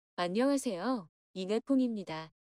단순 소리 크기의 비교를 위한 오디오 입니다.
+10dB
dB-비교-음원_10dB.mp3